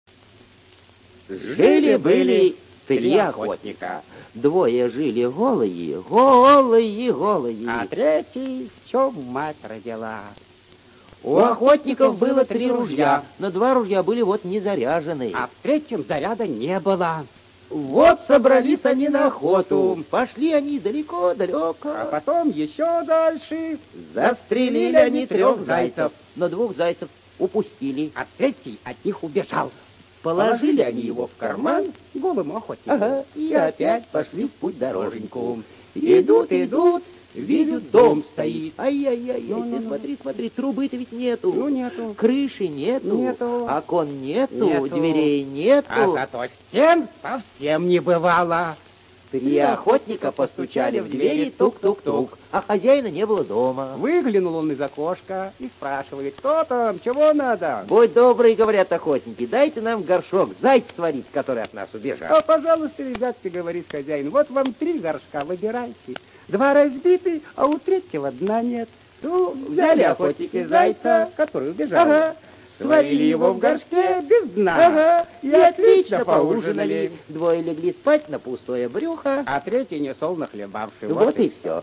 ЖанрДетская литература, Сказки